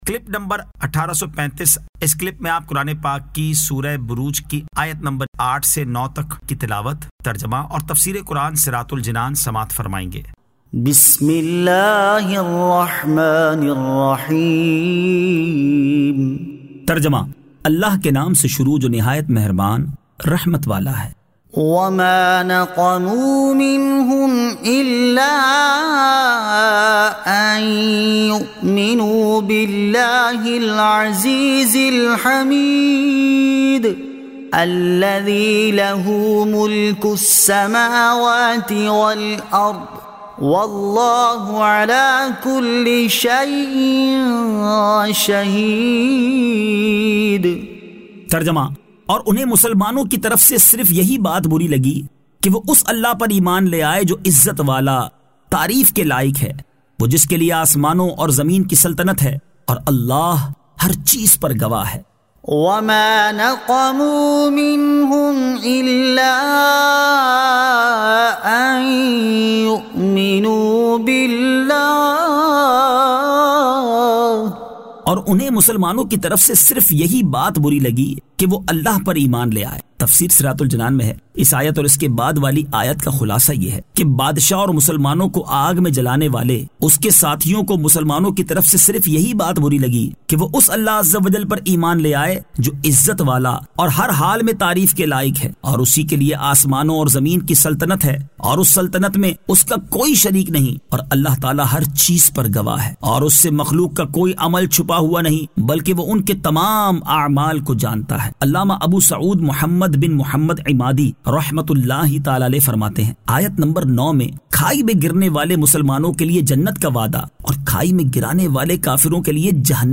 Surah Al-Burooj 08 To 09 Tilawat , Tarjama , Tafseer